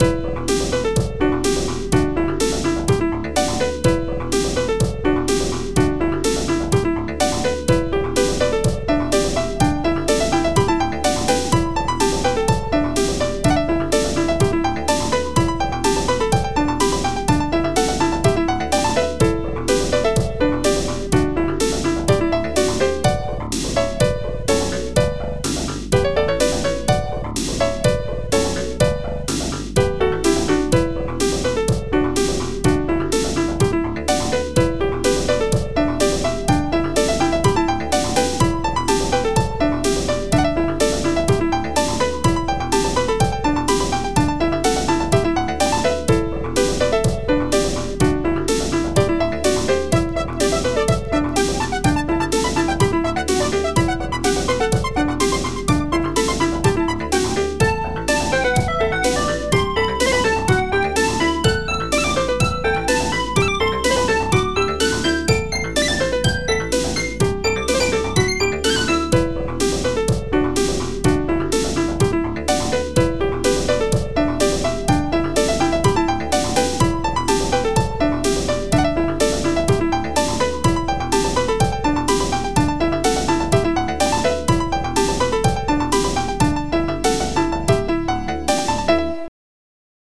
xm (FastTracker 2 v1.04)